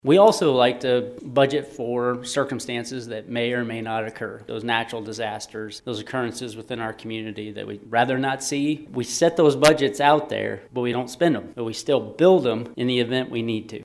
Discussions regarding the overall 2024 budget took place Tuesday during the Manhattan City Commission’s work session.